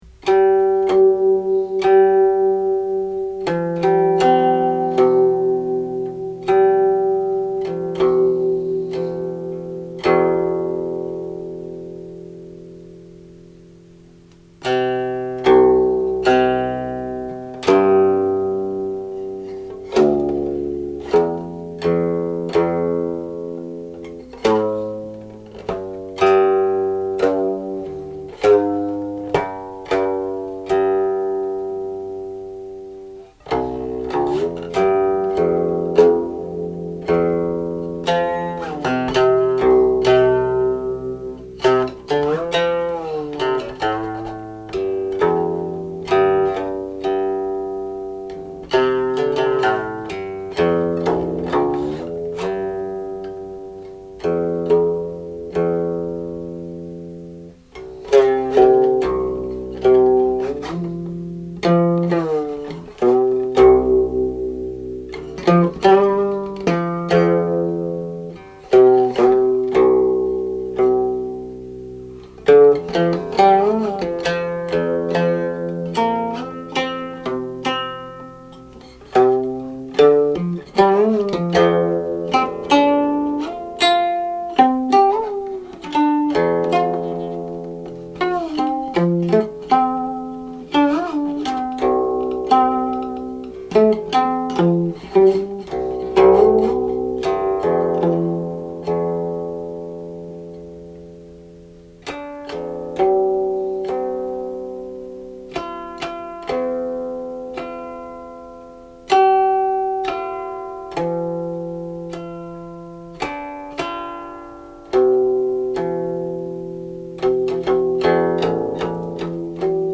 This qin piece has five untitled sections; each section begins with lyrics that indirectly praise the value of good government then continues with the qin playing solo.14 This seems to suggest that the original text suggested either five distinct songs or one song in five distinct sections.
Each section begins with lyrics, then continues with an instrumental solo.
00.00 Music of the modal prelude (taken from the end)
03.51 Harmonic coda